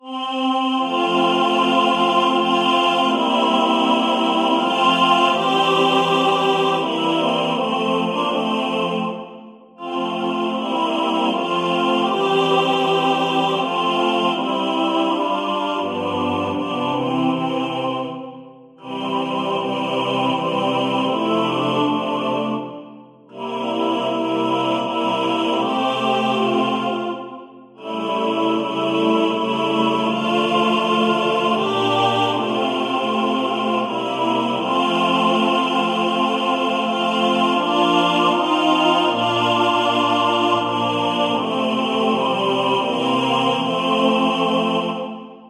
Der vierstimmige Chorsatz